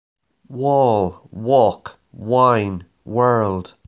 Alfabeto em Ingles Pronuncia Alfabeto Fonético Internacional wall world
Alfabeto-em-Ingles-Pronuncia-Alfabeto-Fonético-Internacional-wall-world.wav